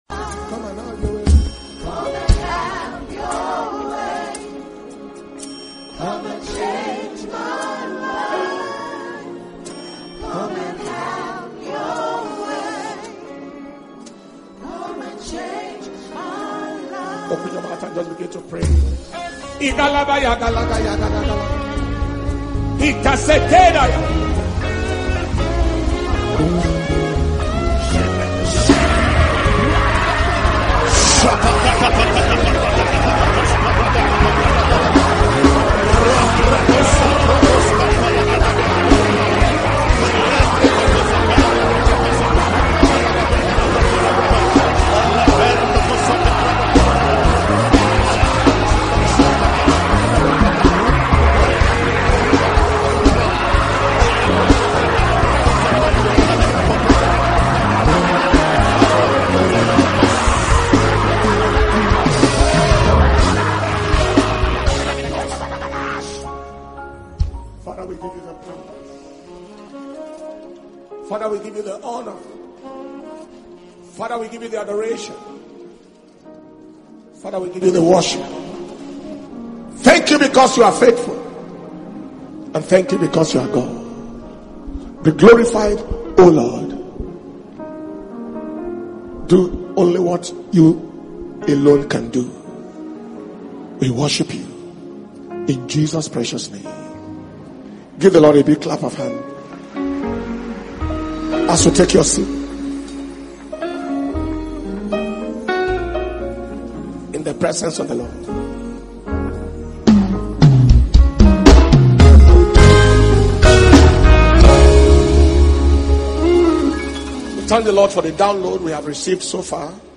International Ministers’ Flaming Fire Conference 2023 – Day 3 Evening Session